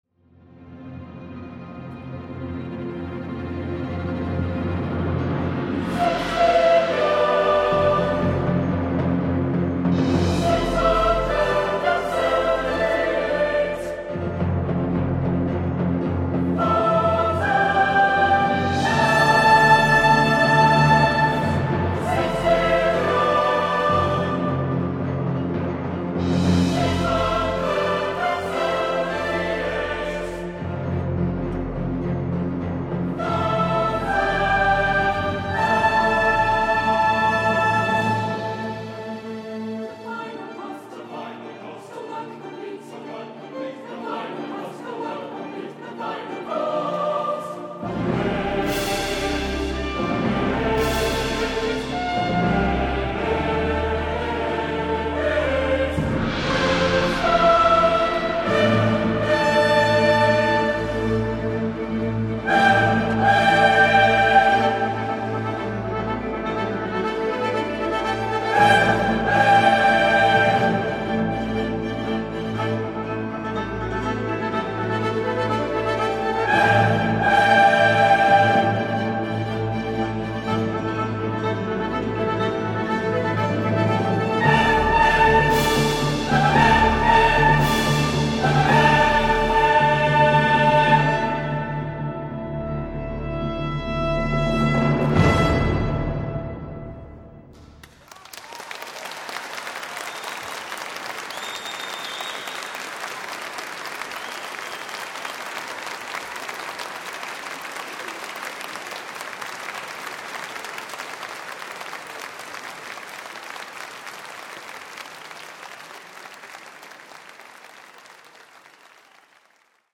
The composition of a contemporary cantata, accompanied by a back-projected film of a speeded up rail journey from London to Bristol from the driver’s point of view, featured an orchestra, soloist and singers to recount the building of the track.
It was recorded at the world premiere at Clifton Cathedral in Bristol on 31 March 2006.